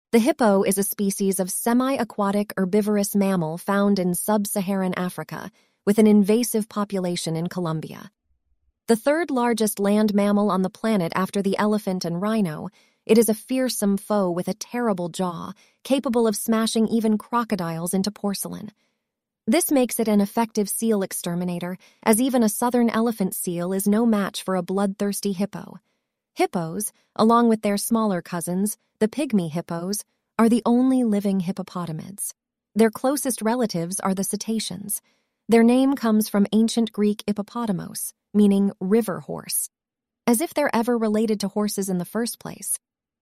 ElevenLabs essentially creates AI voice clips.
ElevenLabs_reads_Hippopotamus_excerpt.mp3